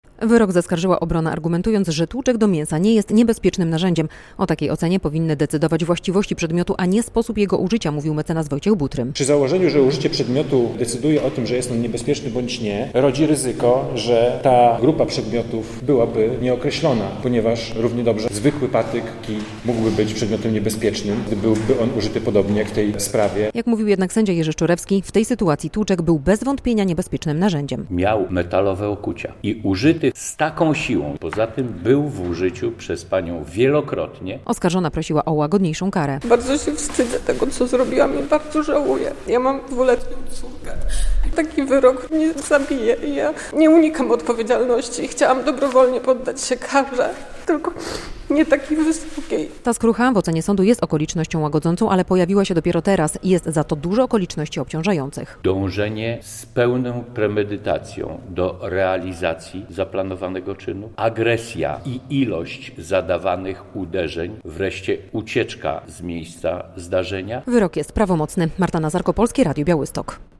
Wyrok za rozbój z użyciem niebezpiecznego narzędzia jakim był tłuczek do mięsa - relacja